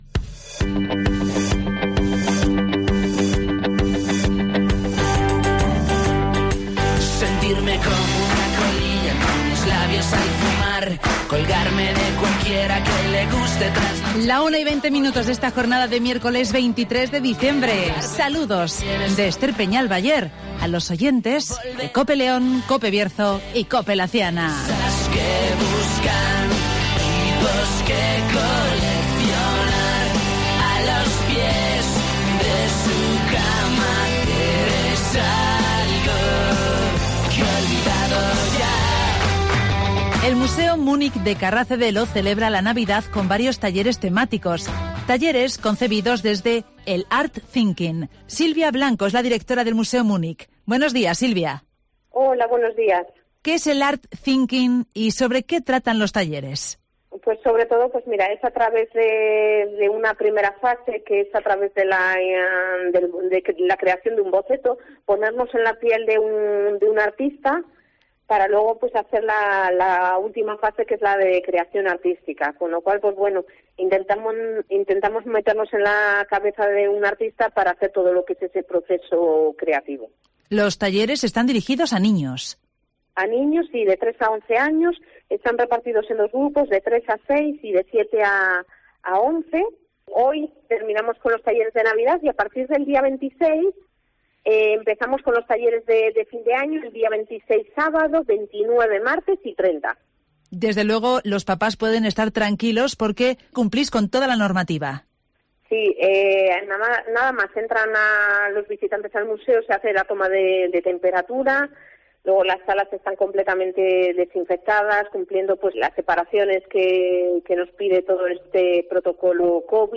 Talleres de Navidad en el Museo Munic de Carracedelo (Entrevista